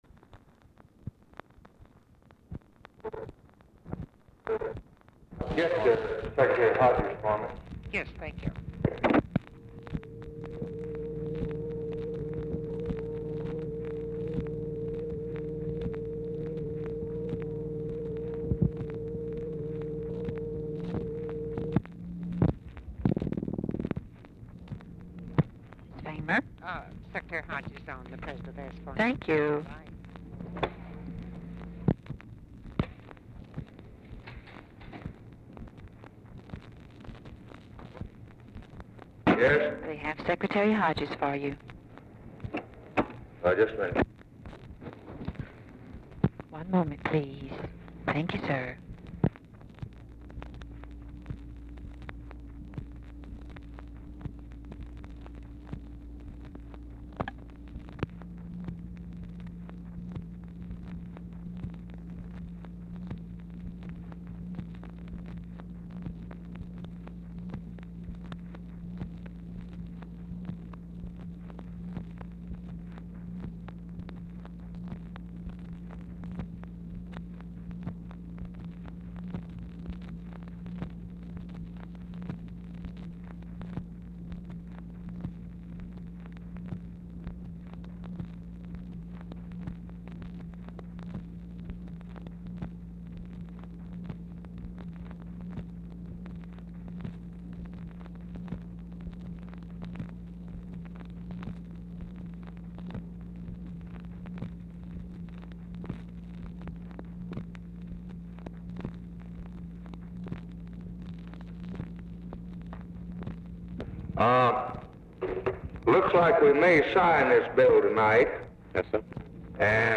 HODGES ON HOLD 1:20 BEFORE CALL; LBJ PUTS HODGES ON HOLD IN MIDDLE OF CONVERSATION, THEN RETURNS
Format Dictation belt
Specific Item Type Telephone conversation